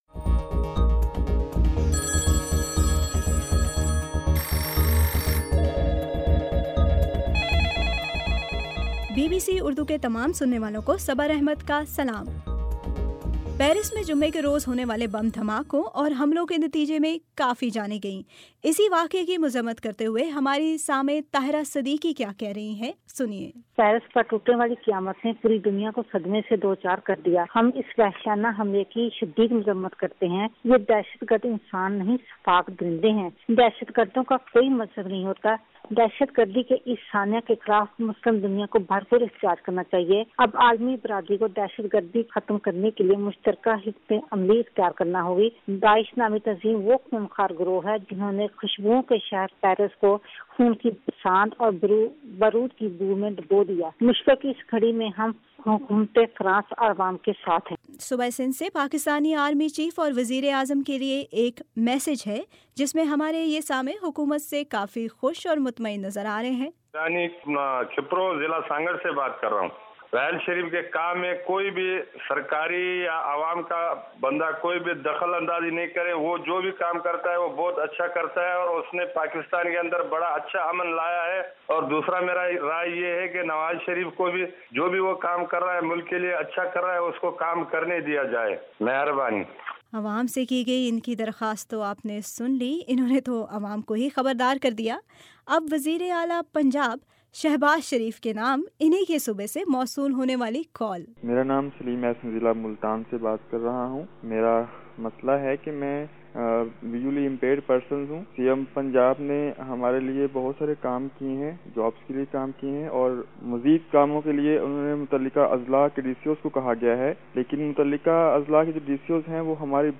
جمرات 19 نومبر کا سامیعن کی کالز پر مبنی پروگرام ٌ آپ کی بات
ہمارے سننے والوں کی کالز پر مبنی ، بی بی سی کا ہفتہ وار پروگرام ” آپ کی باتٌ